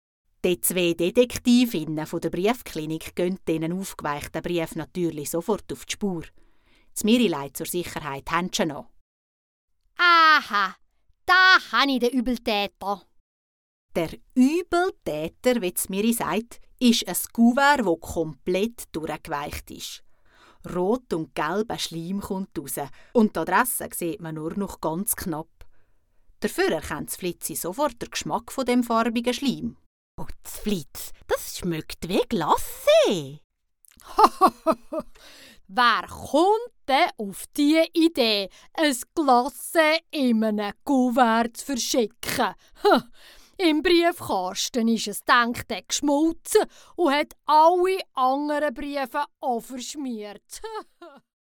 Potz Flitz und Stärnesprudel Folge 2 ★ Kinder Mundart Hörspiel ★